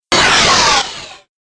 Buttons soundbank 5
Free MP3 buttons sound effects 5
Bip 89